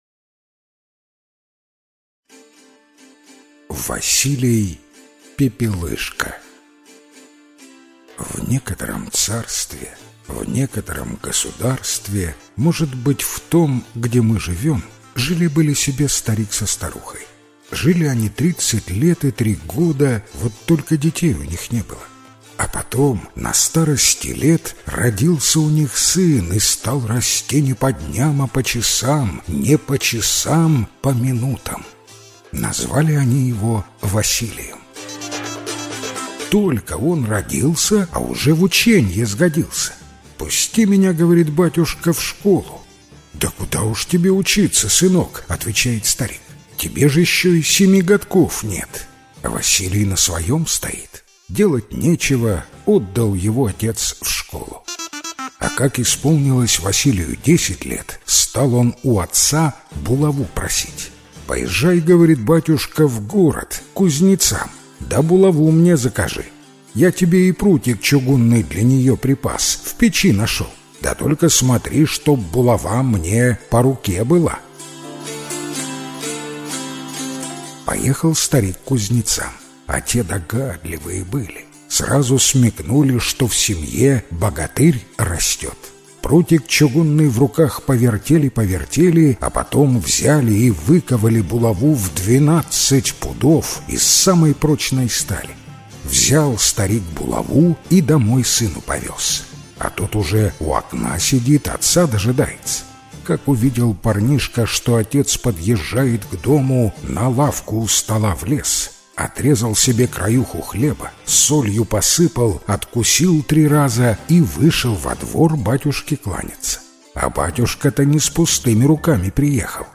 Василий-пеплышка - белорусская аудиосказка - слушать онлайн